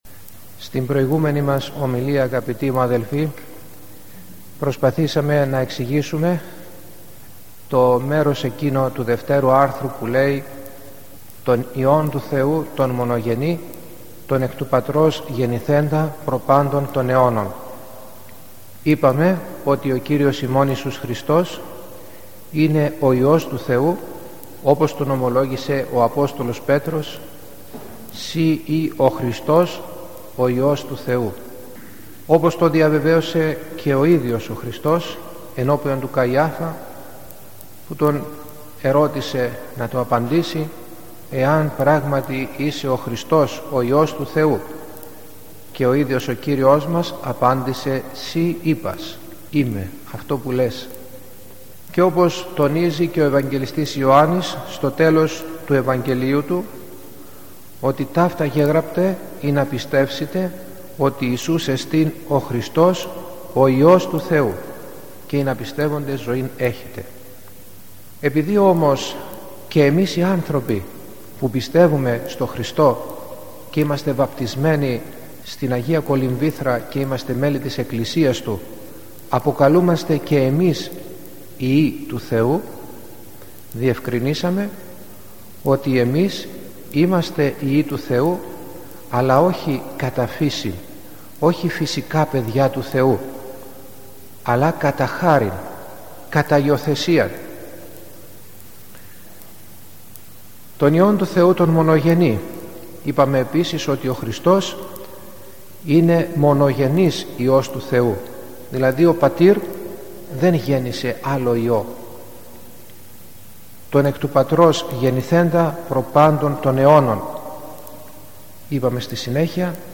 – Ομιλία στο Σύμβολο της Πίστεως (Ομιλία 41η -mp3 2013)